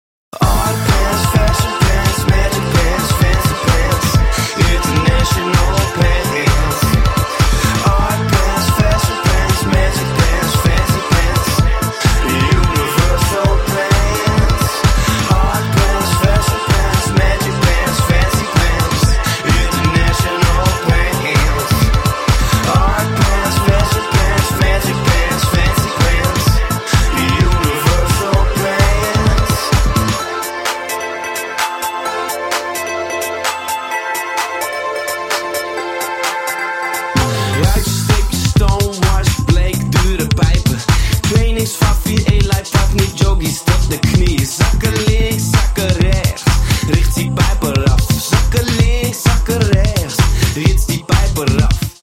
Lo-fi techno funk and fucked up house from Amsterdam.
dirty electronic funk